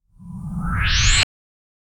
Access granted sci-fi
access--granted--sci-fi-gyxhjq6n.wav